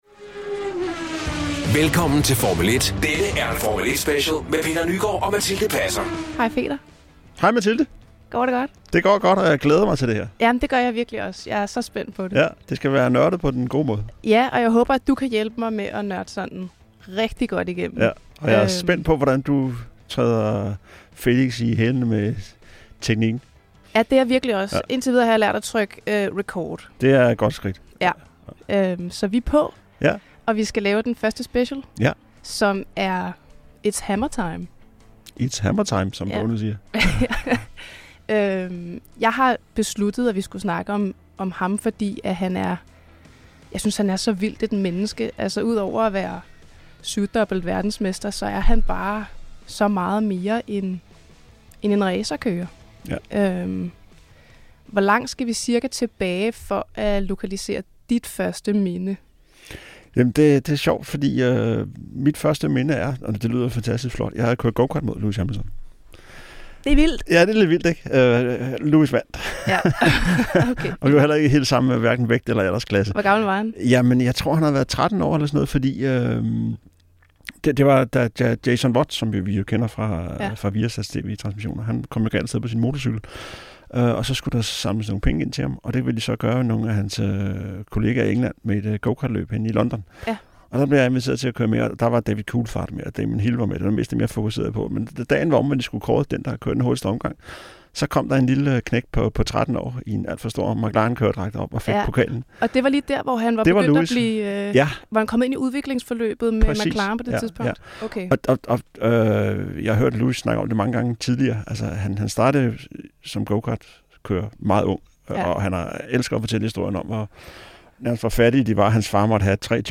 Efter sidste uges fjern-optagelse fra Sao Paulo er Peter tilbage hos Felix i studiet i Skovlunde.